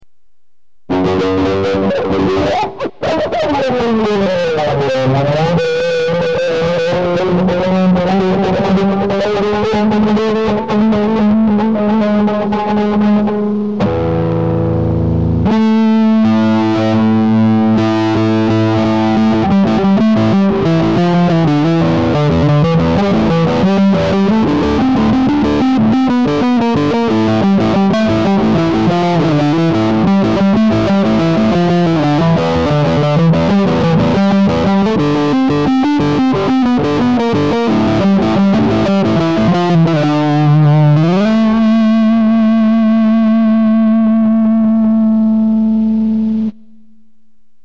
Mozda je malkice preglasno...